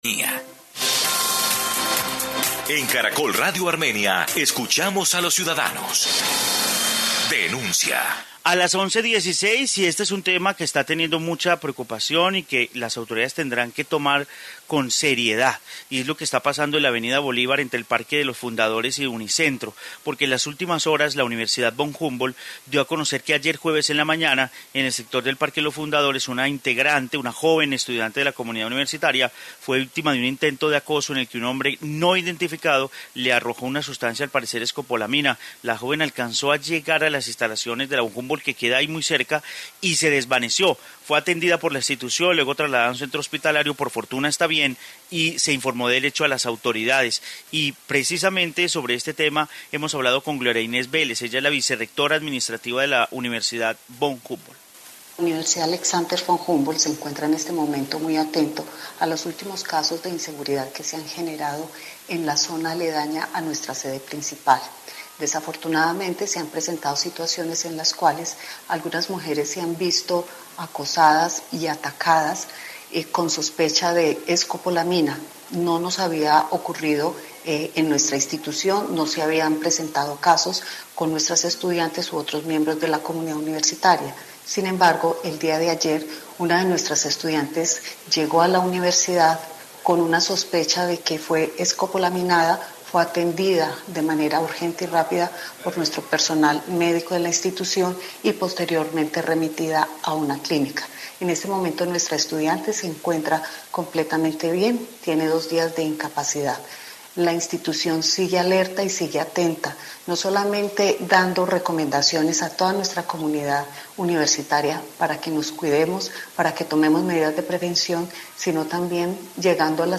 Infome denuncias por acoso en Armenia
Caracol Radio al lado de la gente e hizo un recorrido por el sector donde la mayoría de personas en especial mujeres manifestaron sus temores por la seguridad en la zona